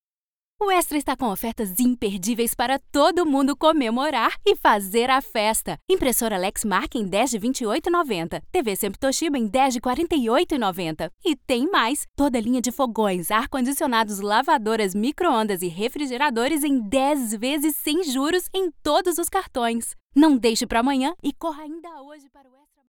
Comercial, Joven, Suave
Comercial